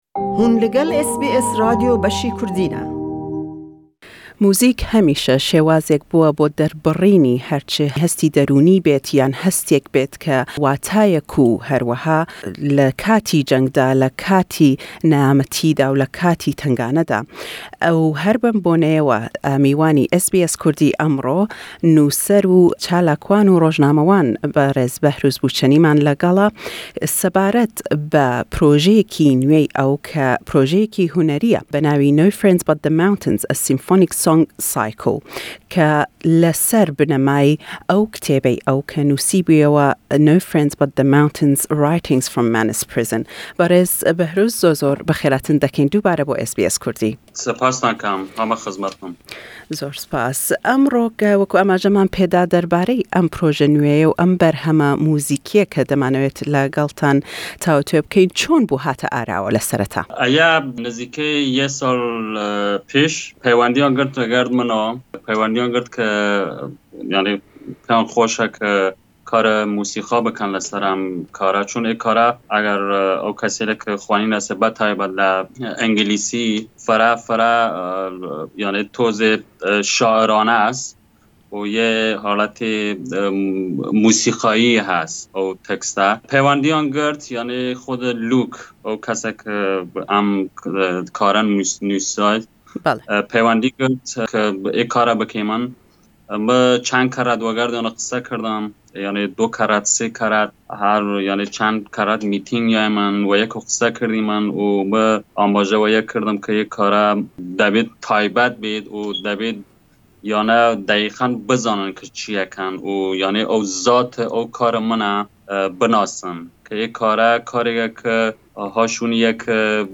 Nuser, rojnamewan, û penaxwazî pêşûyî Kurd Behrûz Bûçanî (Behrouz Boochani) êsta serqallî berhemêkî muzîkî ye be nawî 'No Friend but the Mountains: A Symphonic Song Cycle' le ser binemayî pertûkî ew ke xawenî çendîn xellate. Le em lêdwane da pirsîyar dekeyn le Berêz Bûçanî sebaret be em berheme û naweroke key.